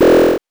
powerup_4.wav